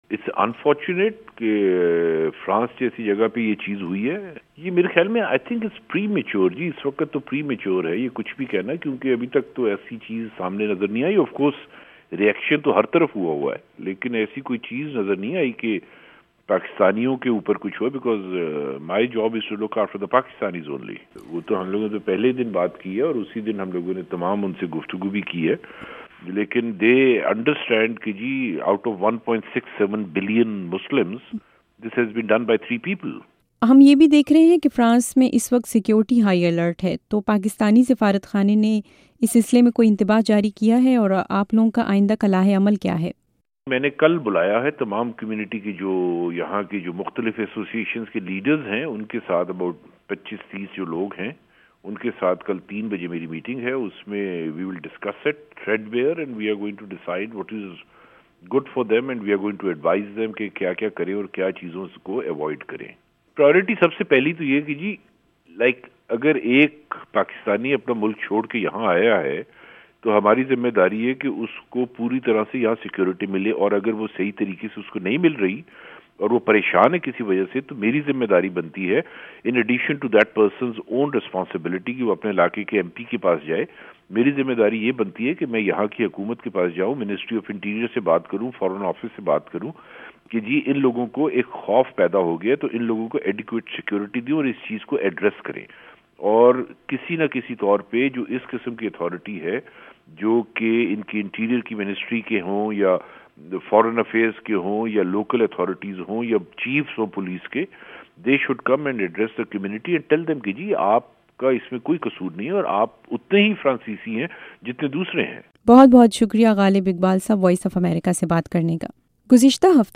فرانس میں پاکستانی سفیر، غالب اقبال سے خصوصی انٹرویو